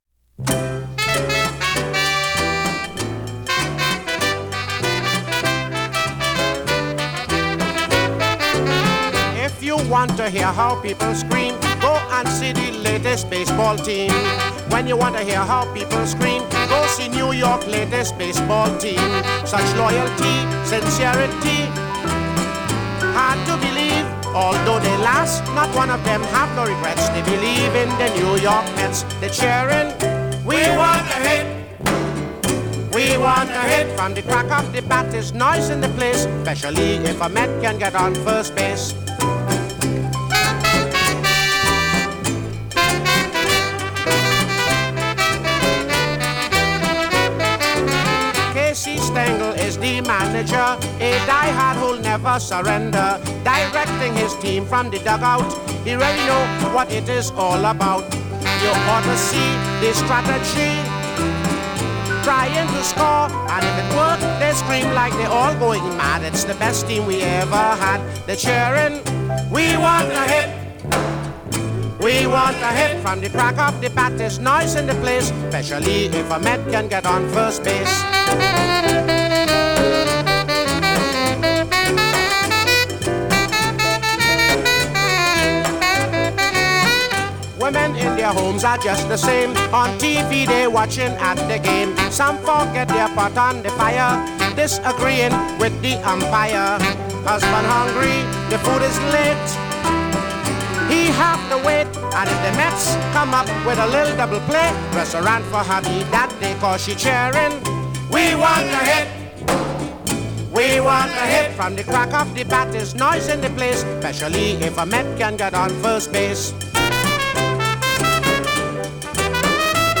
calypso singer